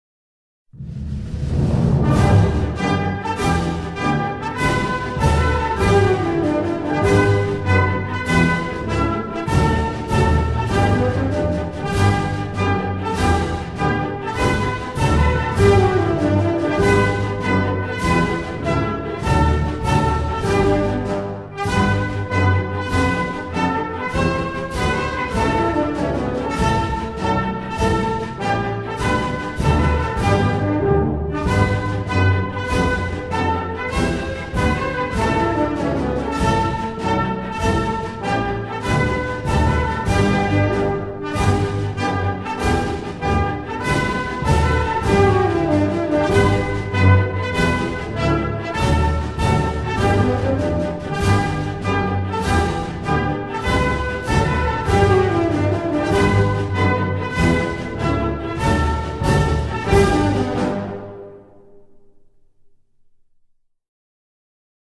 National Anthem
Tunisia_National_Anthem1.mp3